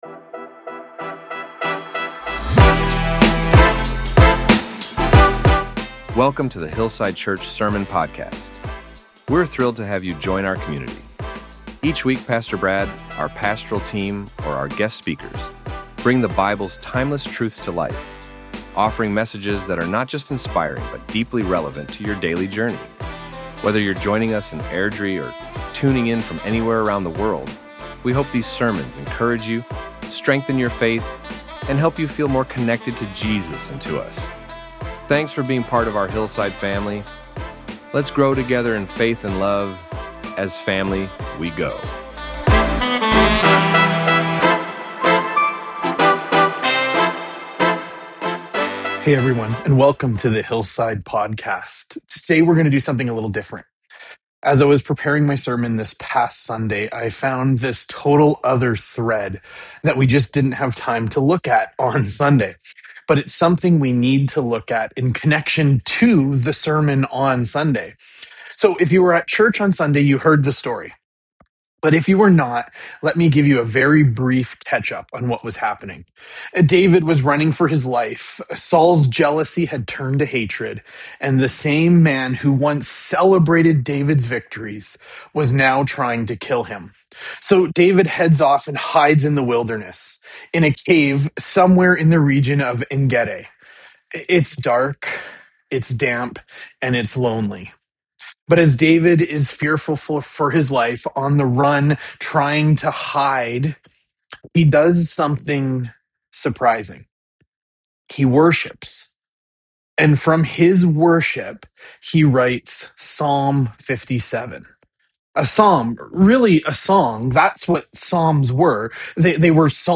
In “The Worship Incident”, we see how David turned to worship even in the darkest places of his life. When fear and uncertainty surrounded him, his heart chose to trust and praise God. This message was recorded especially for our podcast and online listeners — a powerful reminder that even in the caves, our worship can rise.